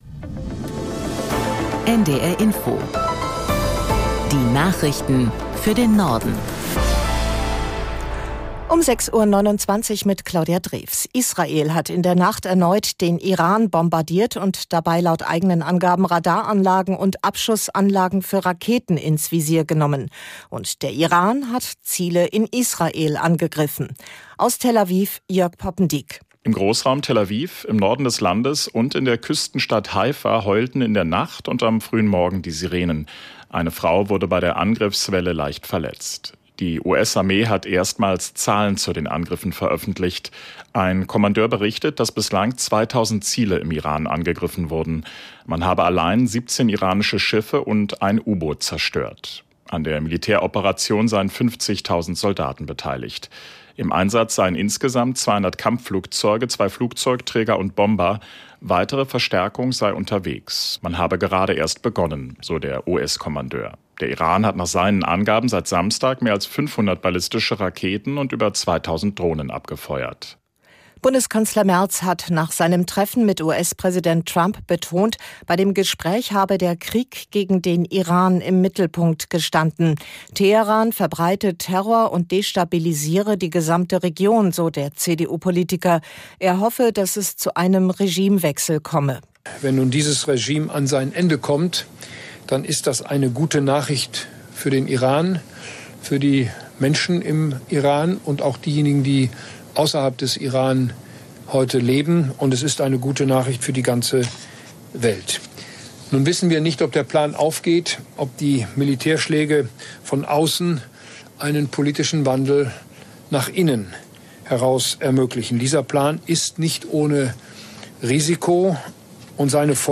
NDR Info Nachrichten